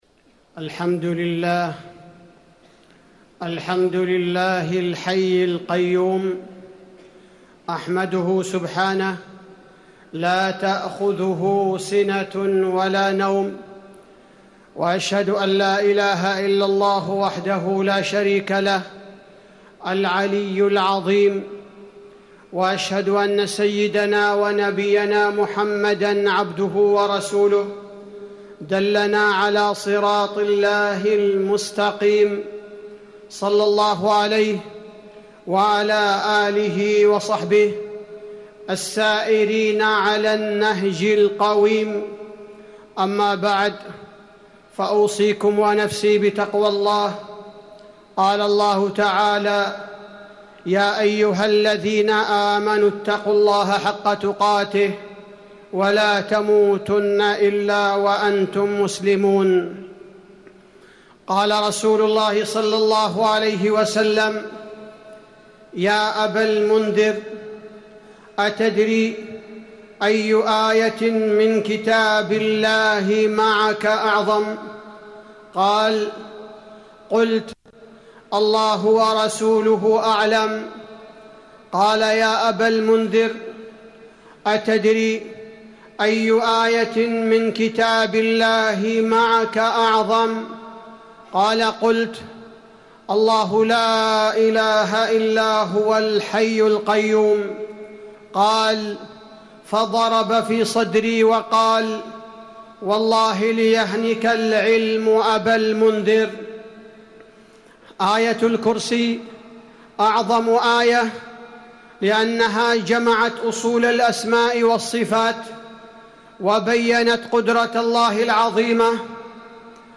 تاريخ النشر ١٠ صفر ١٤٤٠ هـ المكان: المسجد النبوي الشيخ: فضيلة الشيخ عبدالباري الثبيتي فضيلة الشيخ عبدالباري الثبيتي وقفات مع آية الكرسي